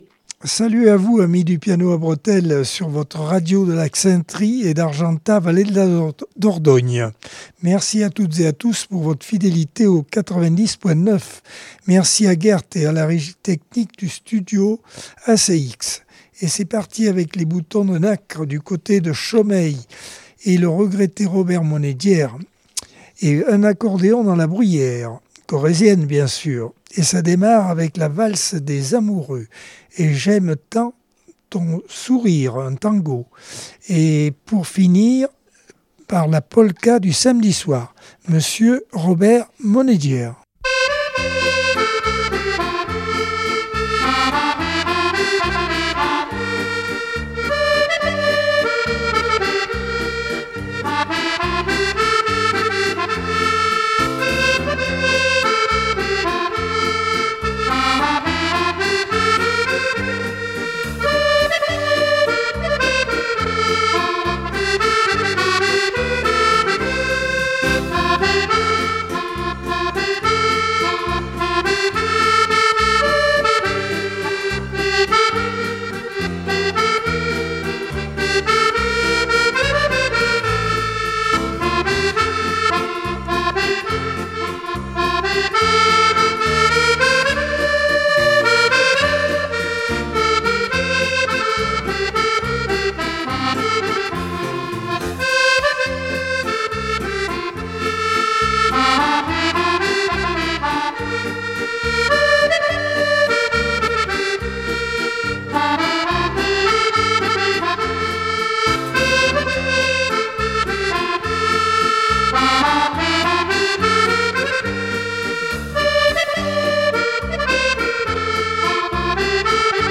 Accordeon 2025 sem 46 bloc 1 - Radio ACX